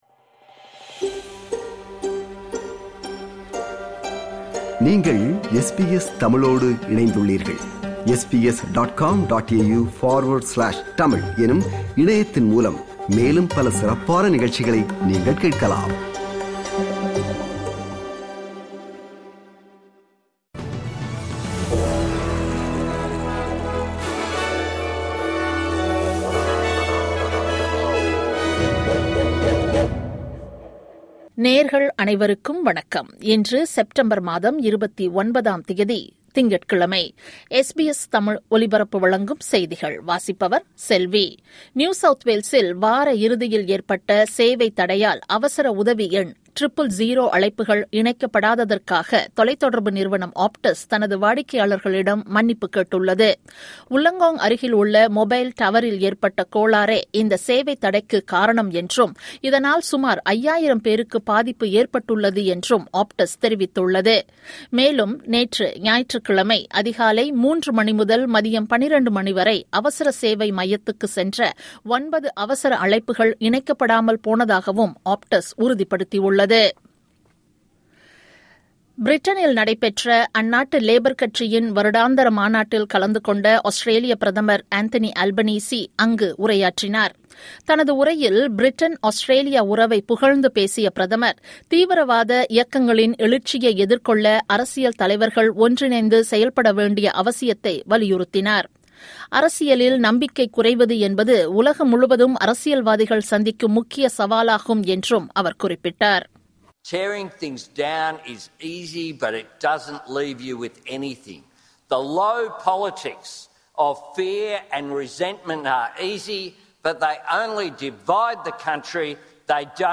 இன்றைய செய்திகள்: 29 செப்டம்பர் 2025 திங்கட்கிழமை
SBS தமிழ் ஒலிபரப்பின் இன்றைய (திங்கட்கிழமை 29/09/2025) செய்திகள்.